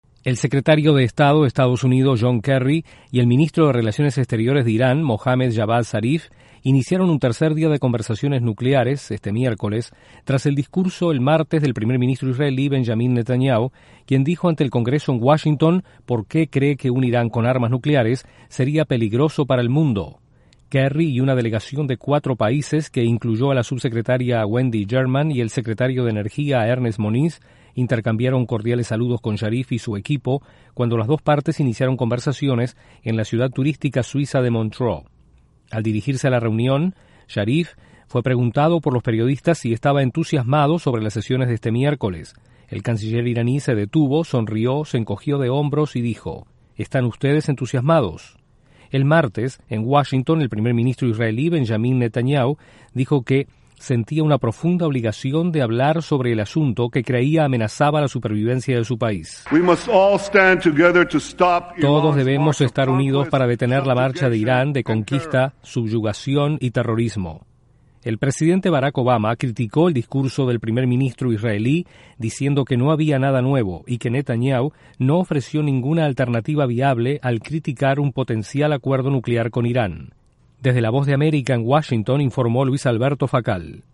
Se lleva a cabo este miércoles en Suiza el tercer día de conversaciones sobre el programa nuclear de Irán. Desde la Voz de América en Washington informa